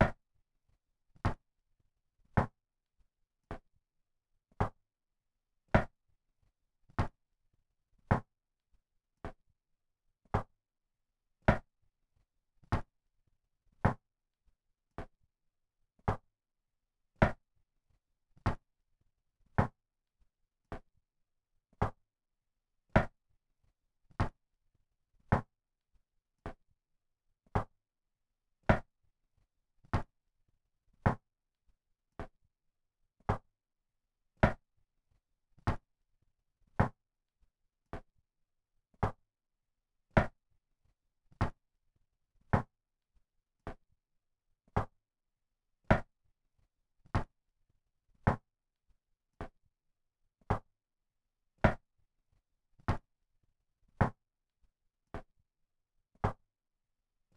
assignments:footsteps.wav